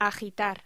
Locución: Agitar